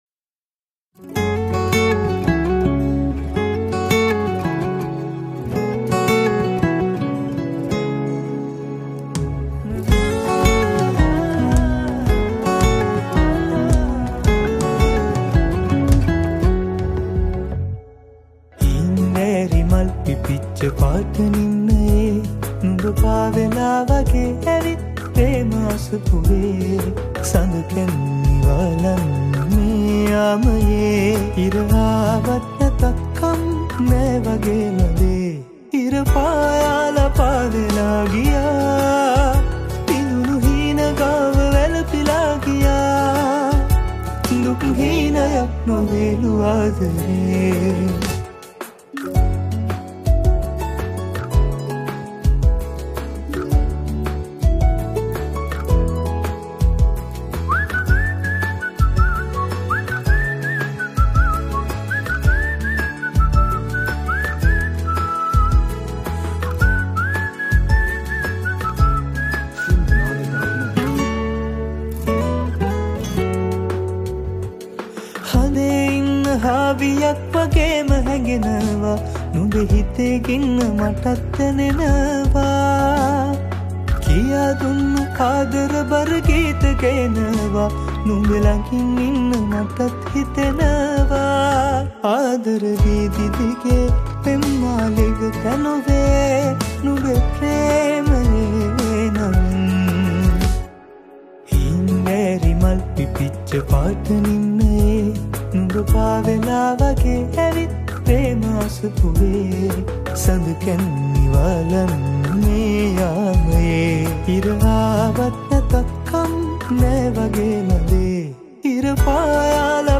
Guitar
Keyboards